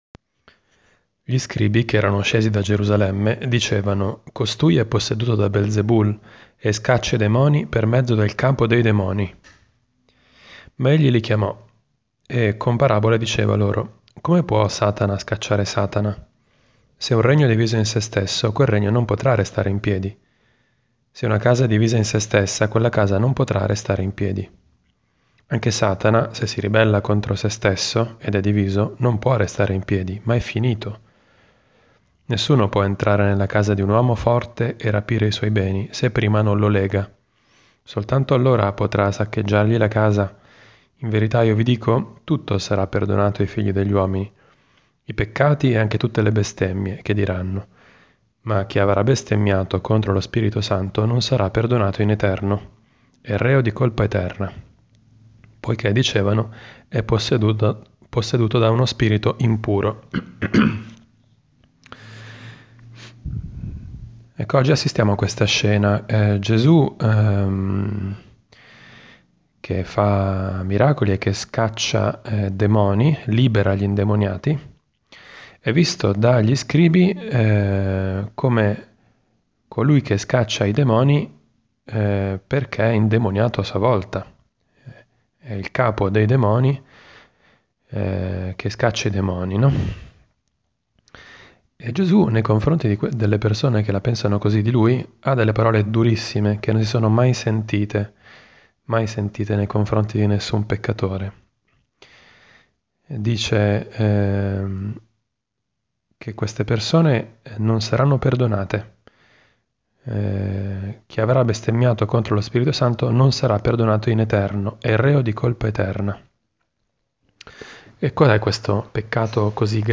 Commento al vangelo (Mc 3,22-30) del 22 gennaio 2018, lunedì della III domenica del Tempo Ordinario.